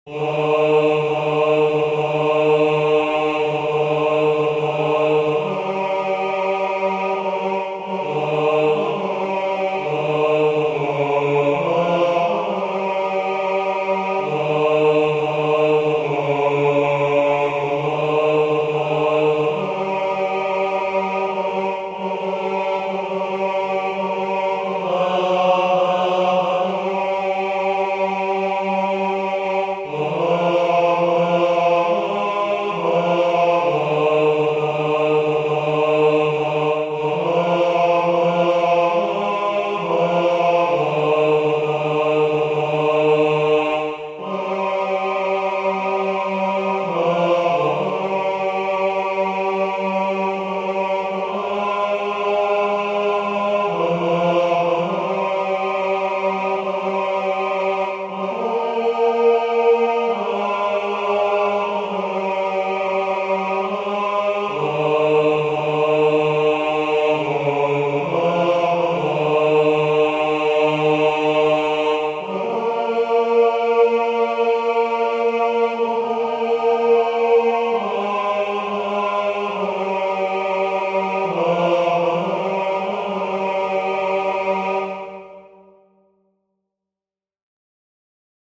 O_Holy_Night_tenor.mp3